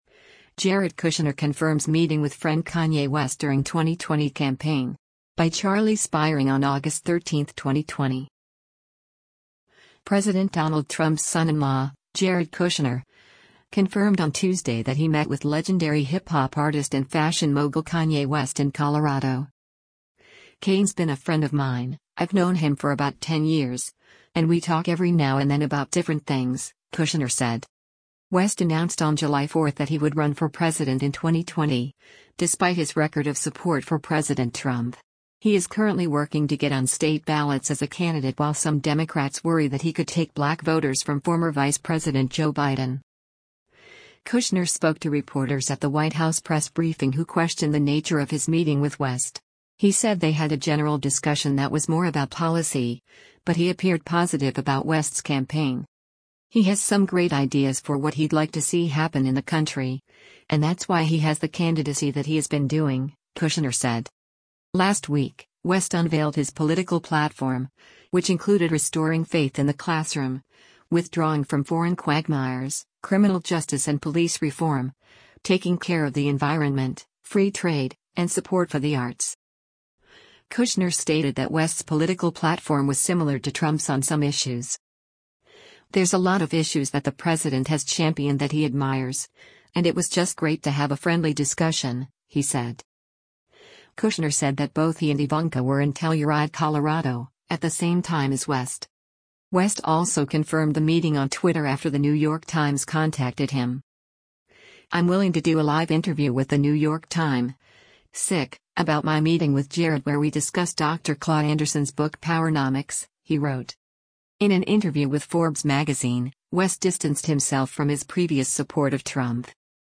Kushner spoke to reporters at the White House press briefing who questioned the nature of his meeting with West.